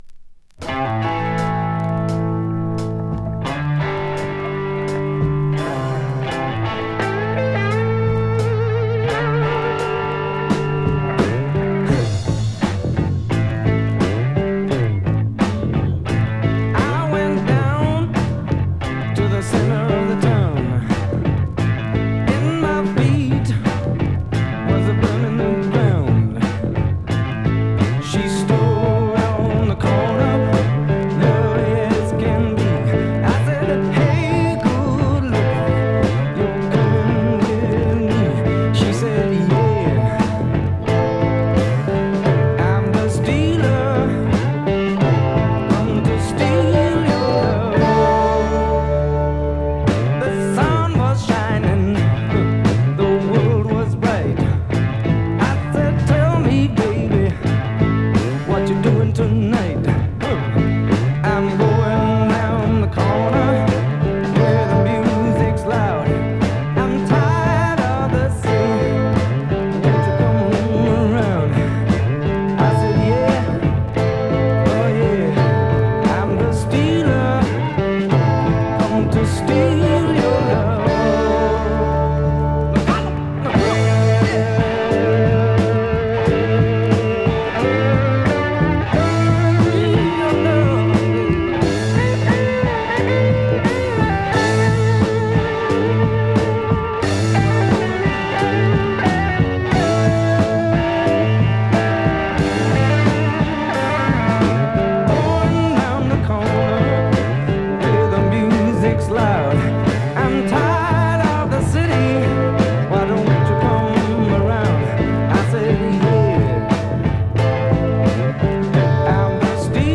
このテンポのロックはいいですね。